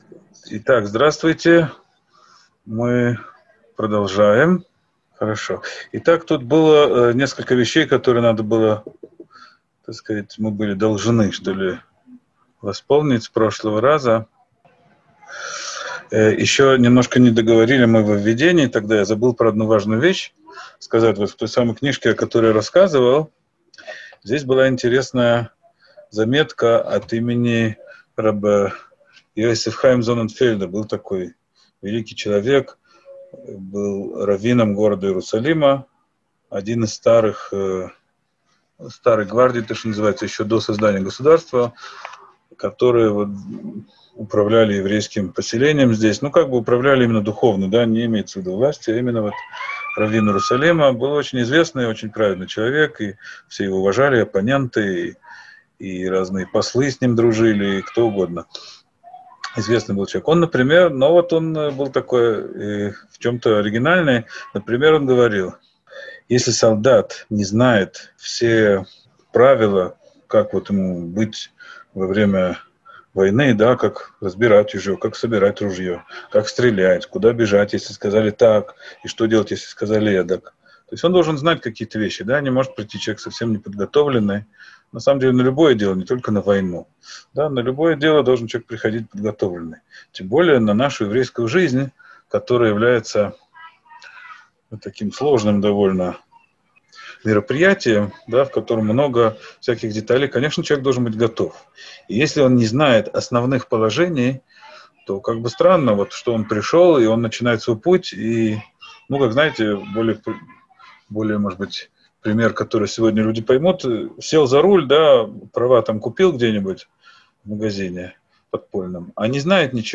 Урок 2. Плодитесь и размножайтесь (ч. 2).